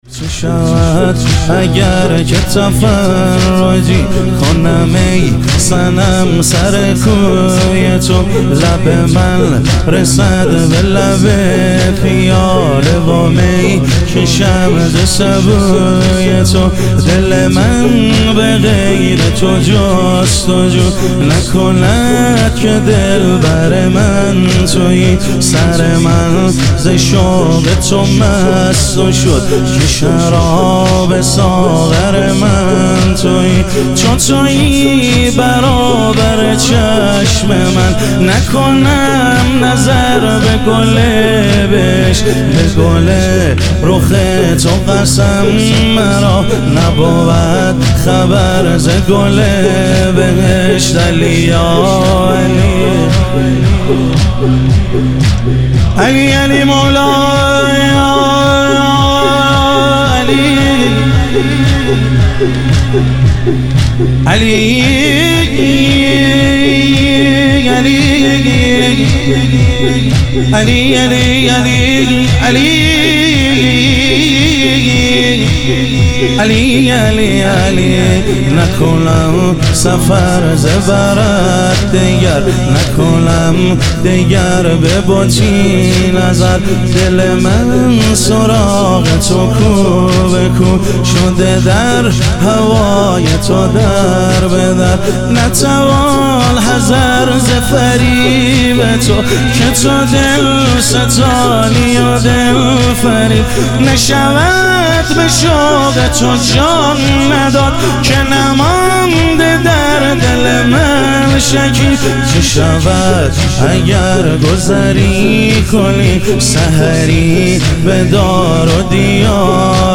شهادت حضرت سلطانعلی علیه السلام - تک